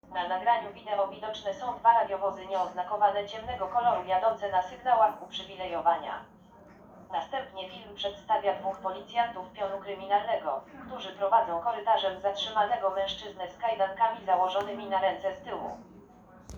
Nagranie audio Audiodeskrypcja_filmu_Zatrzymany_oszust.m4a